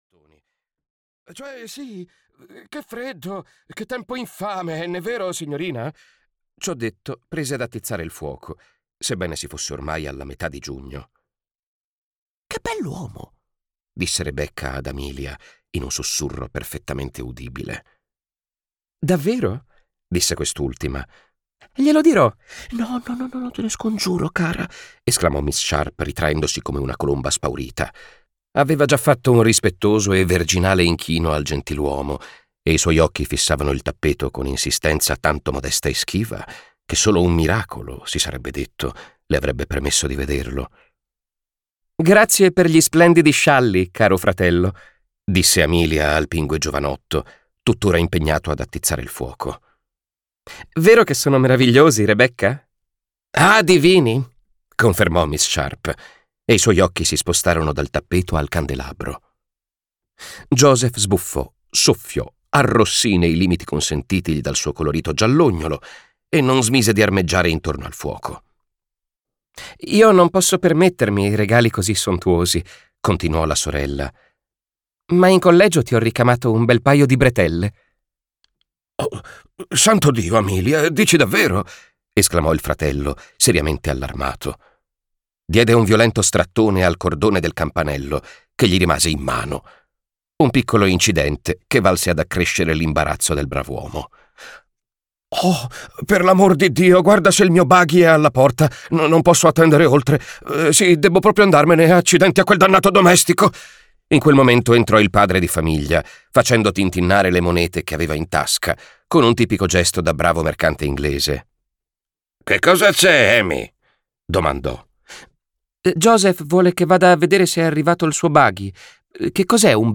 "La fiera della vanità" di William Makepeace Thackeray - Audiolibro digitale - AUDIOLIBRI LIQUIDI - Il Libraio